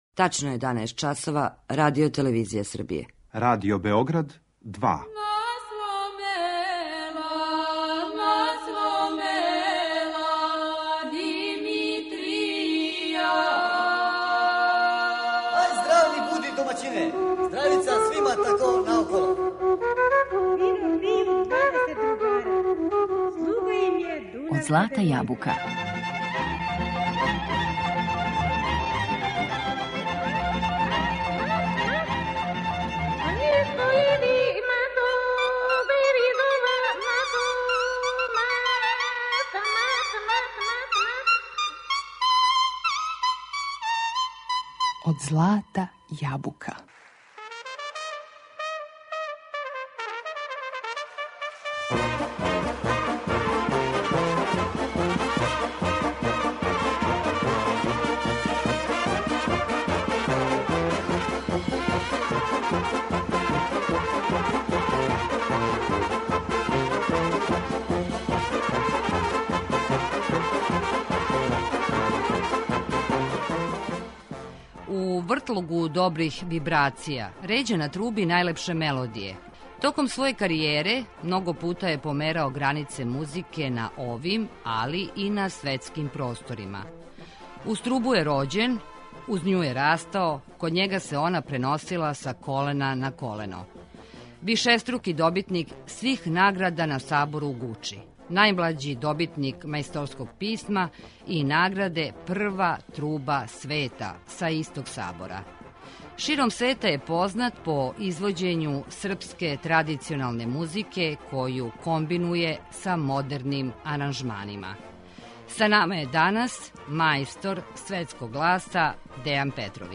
У вртлогу добрих вибрација ређа на труби најлепше мелодије којима је удахнуо нови живот.
Широм света је познат по извођењу српске традиционалне музике коју комбинује са модерним аранжманима. Гост данашње емисије Од злата јабука је ‒ Дејан Петровић.